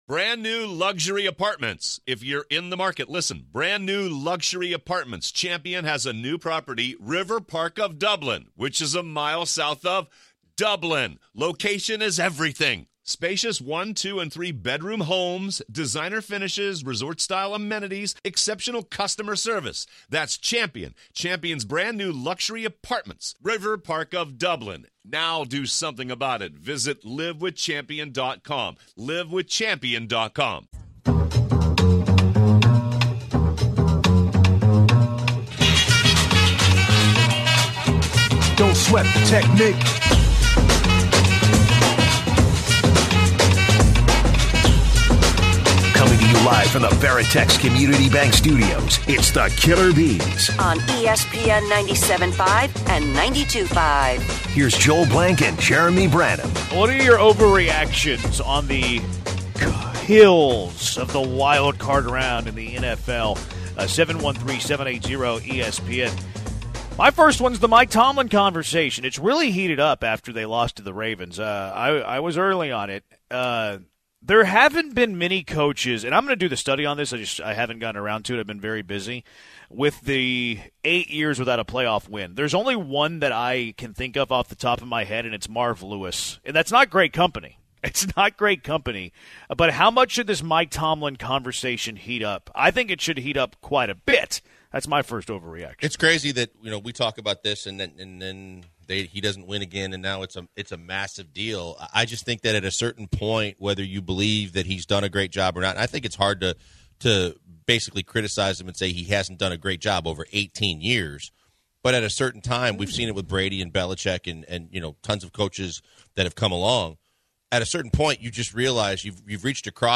Our NFL Wildcard round OVERREACTIONS! That turned into a passionate conversation with callers on Mike Tomlin's status with the Steelers; should the Steelers move on?